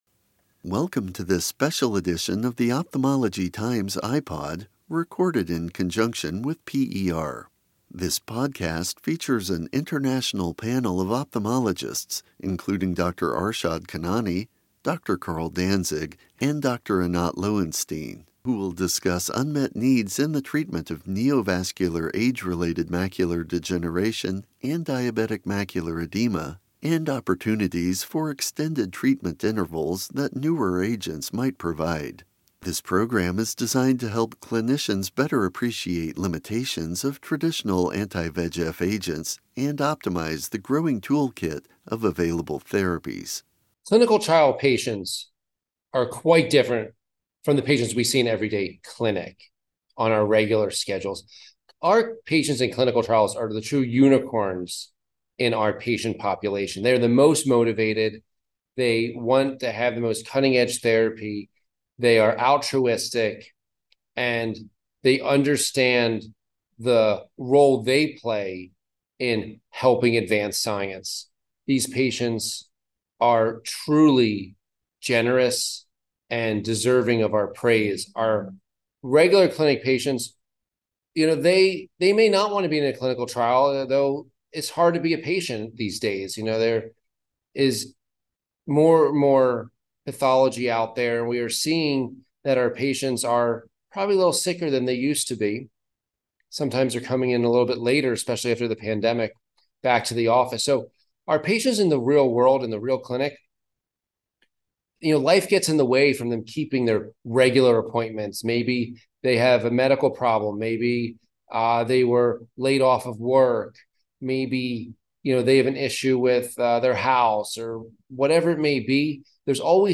This podcast features an international panel of ophthalmologists